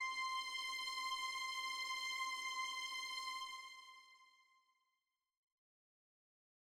ITA String C5.wav